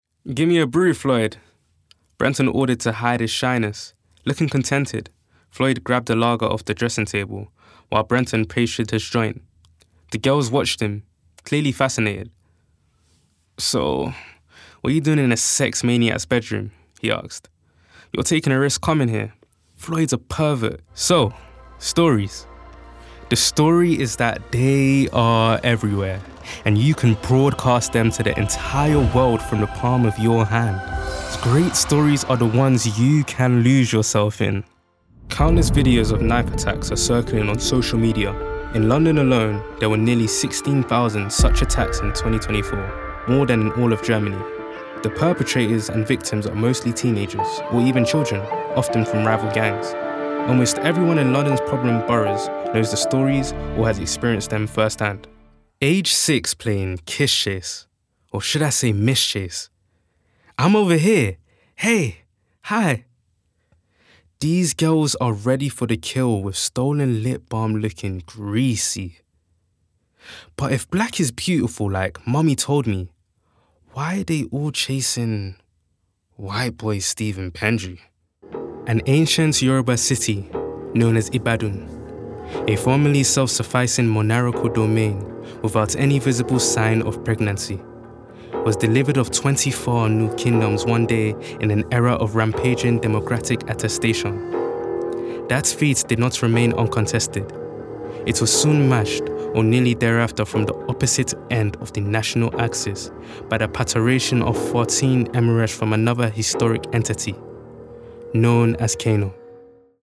Native voice:
MLE
Voicereel:
BRITISH ISLES: Contemporary RP